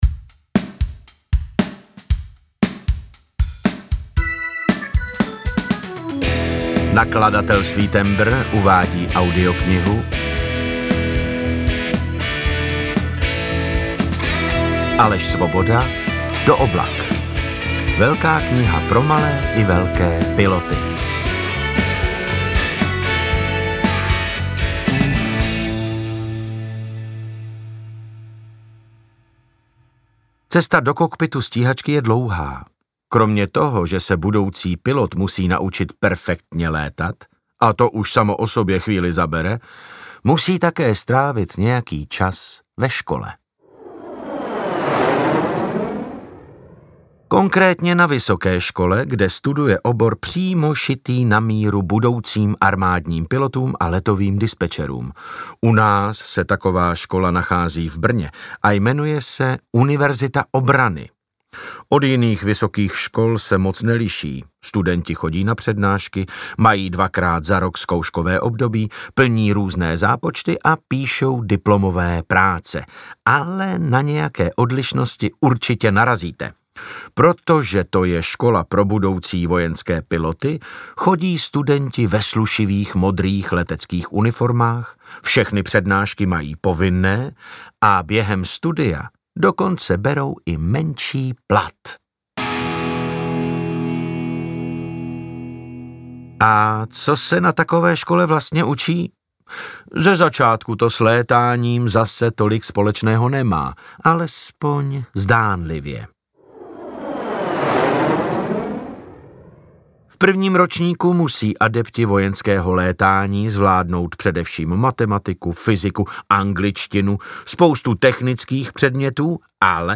Audio knihaDo oblak – Velká kniha pro malé i velké piloty
Ukázka z knihy
• InterpretDavid Novotný